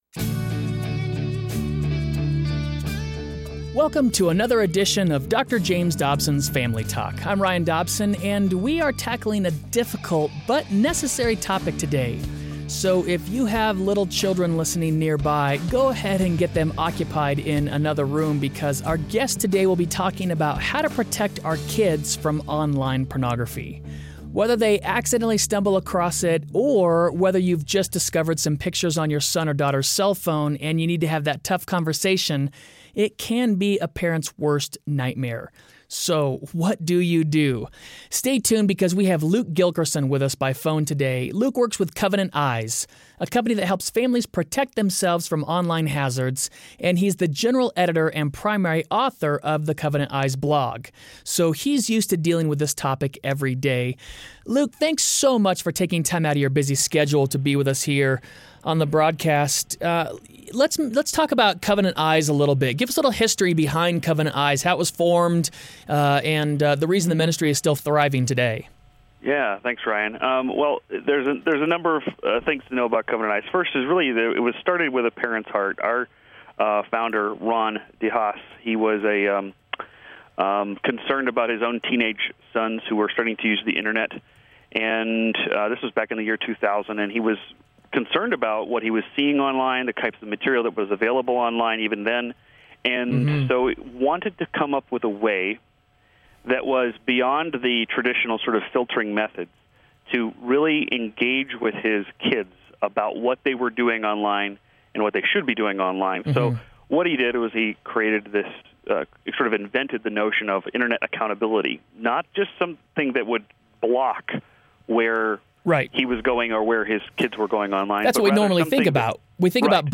Learn how to protect and prepare your children to navigate potential Internet dangers, like pornography, and keep your family safe on the World Wide Web. Don't miss this practical discussion.